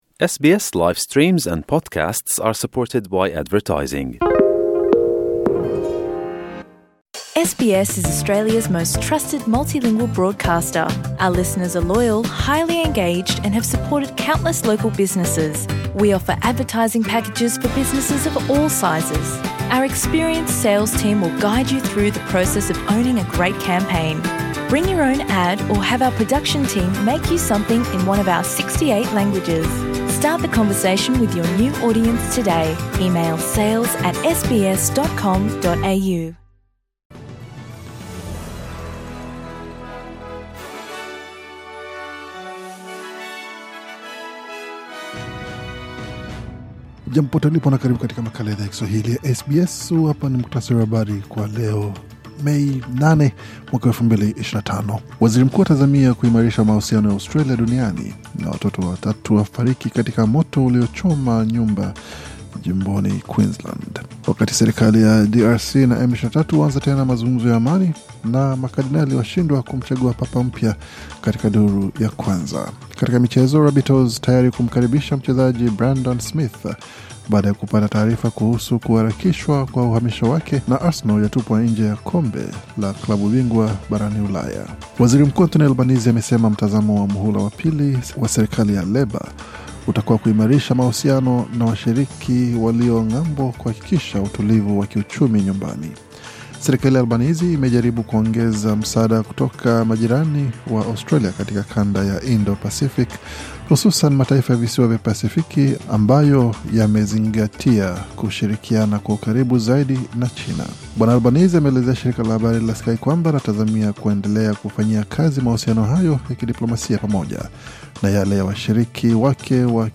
Taarifa ya Habari 8 Mei 2025